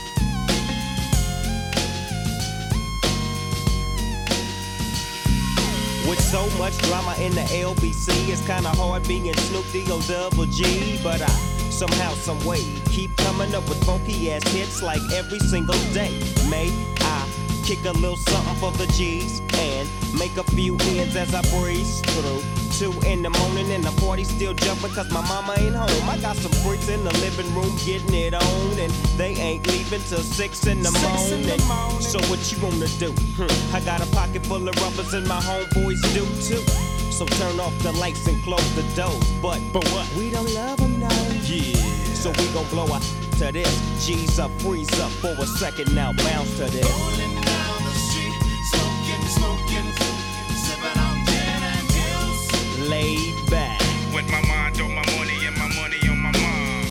Tip The Creator: rap